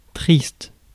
Ääntäminen
IPA: /tʁist/